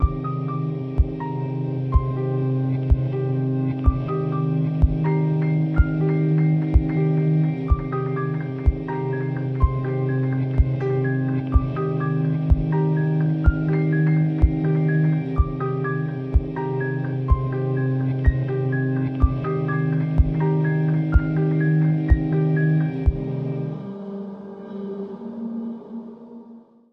Μουσική
Δείγματα από τη μουσική της παράστασης